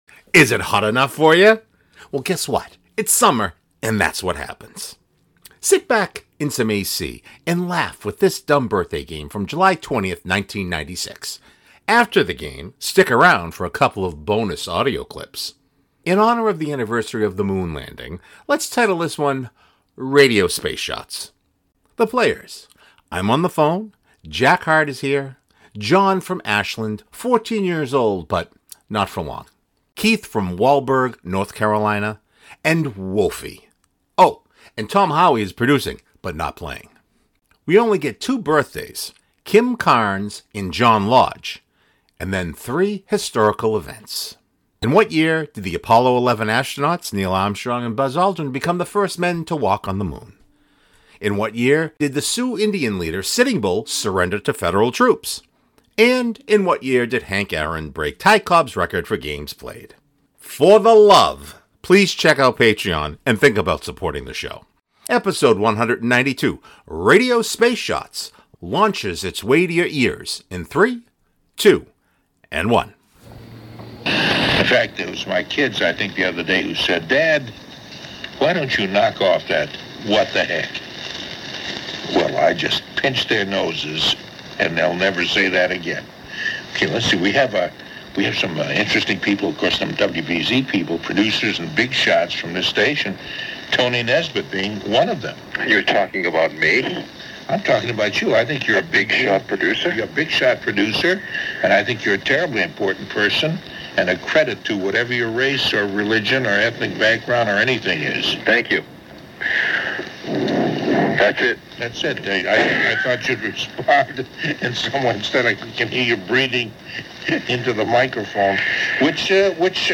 Sit back in some AC and laugh with this DBG from July 20th, 1996. After the game, stick around for a couple of bonus audio clips.